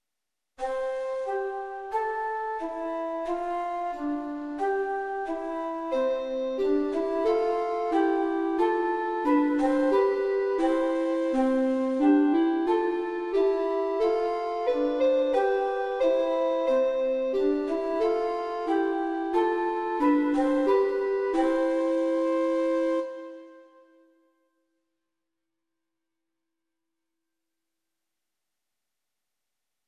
Canon voor 2 stemmen
tweestemmig